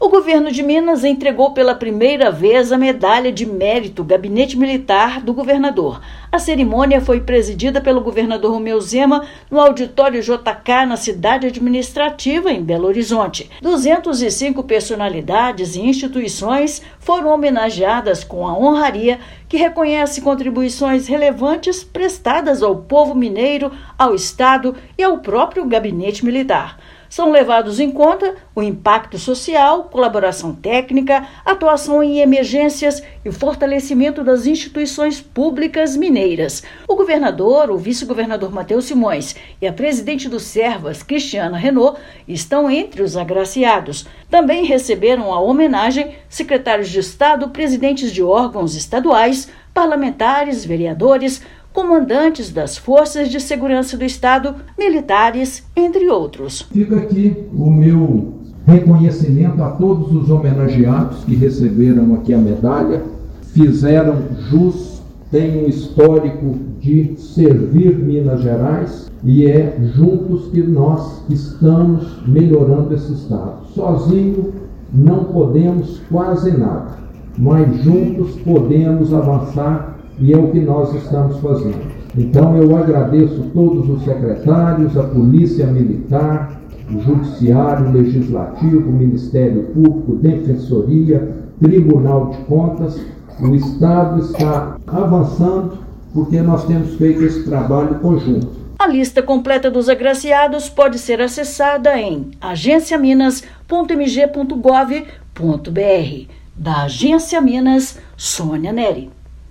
[RÁDIO] Governo de Minas realiza primeira edição da Medalha de Mérito Gabinete Militar do Governador
Honraria reconhece mais de 200 personalidades e instituições que contribuíram para o fortalecimento das ações do Estado e do Gabinete Militar. Ouça matéria de rádio.